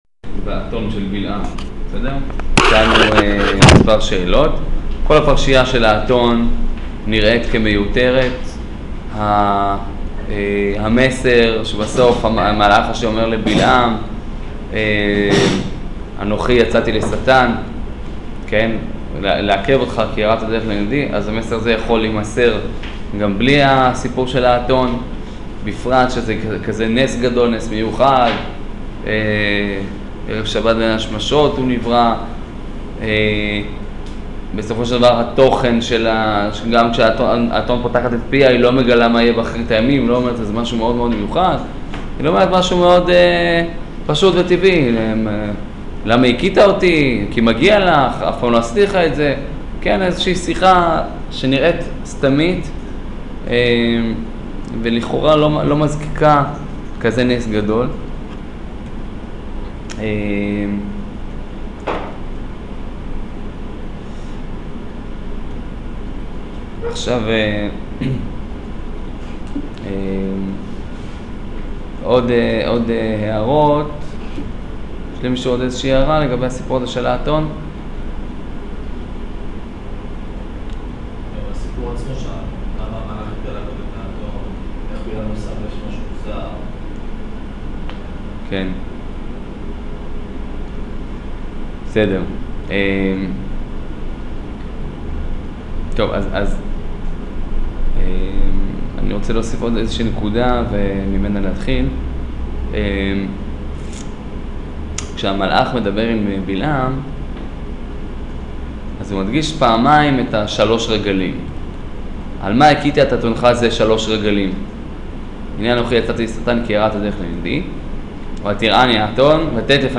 שיעור פרשת בלק